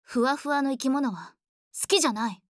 第四则配音片段：
高冷与傲娇并存的声线，这才是绫女特有的魅力。
真好奇这样的傲娇声线出自哪位声优小姐姐~